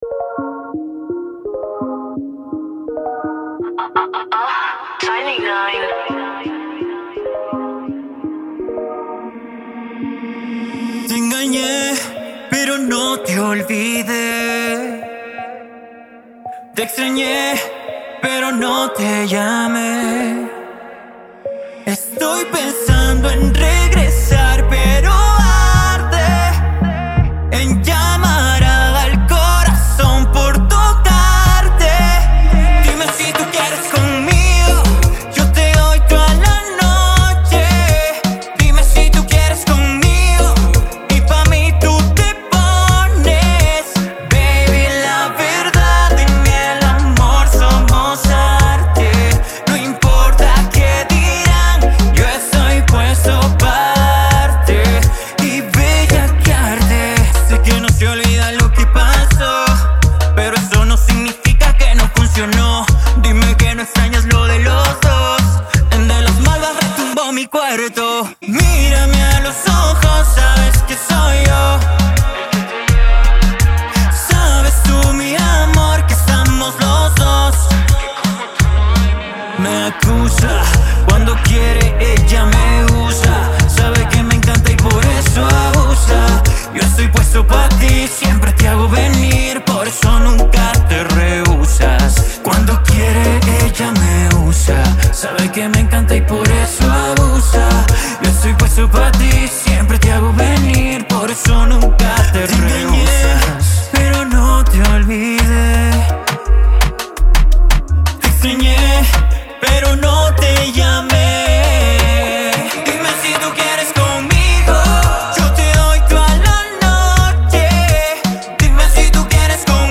reggaeton con un sello íntimo y poético